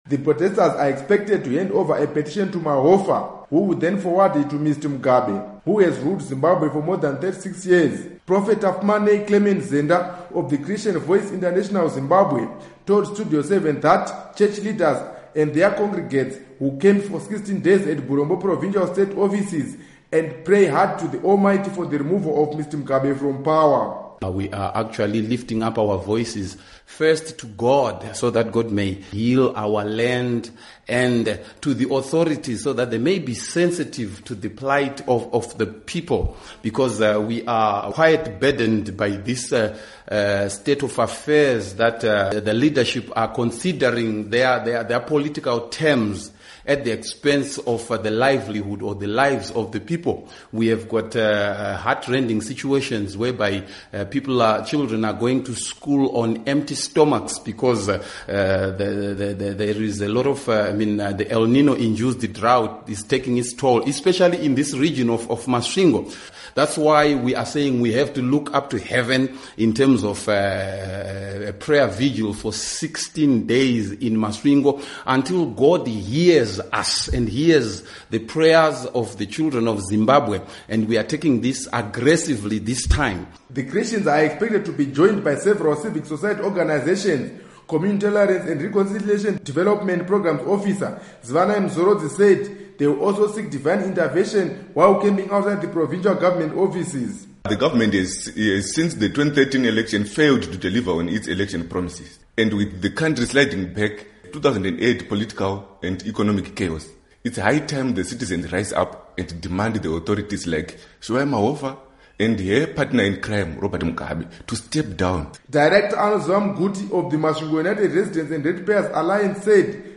Report on Prayer Vigil